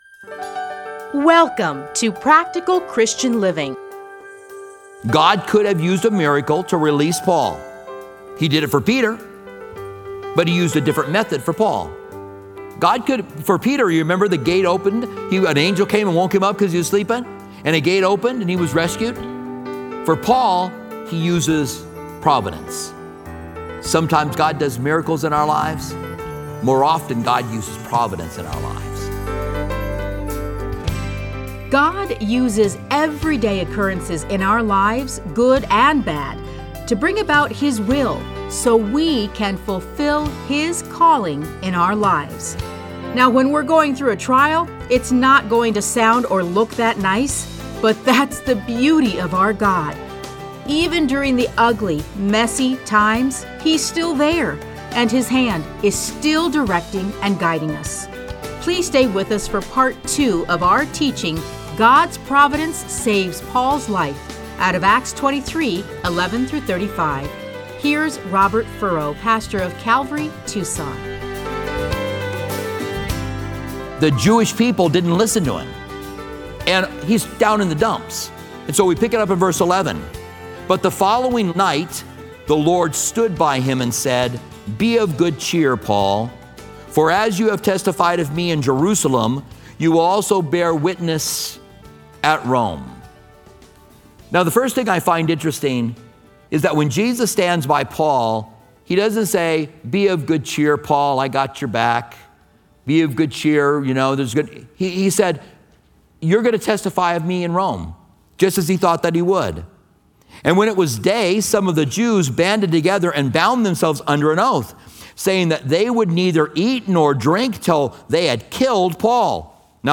Listen to a teaching from Acts 23:11-35.